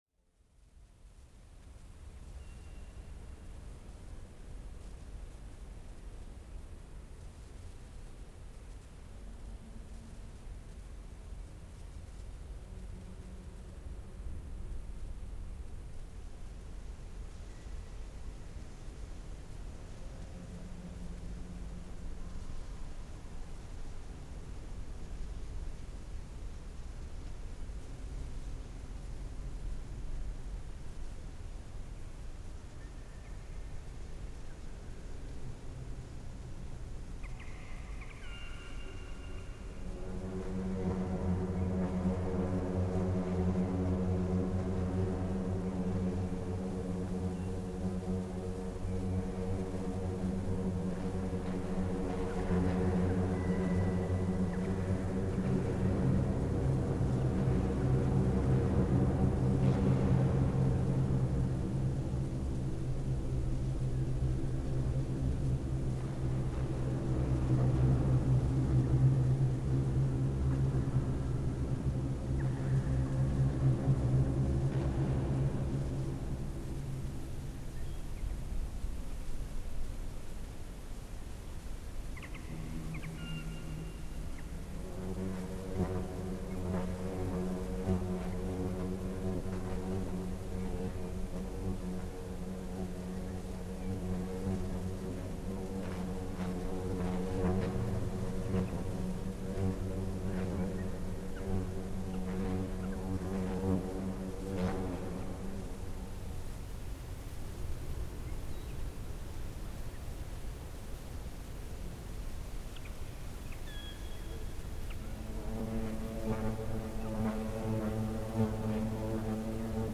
Nature-Symphony 69a (Flies as musicians 4a: Half-diminished seventh (impl